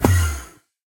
teleport.ogg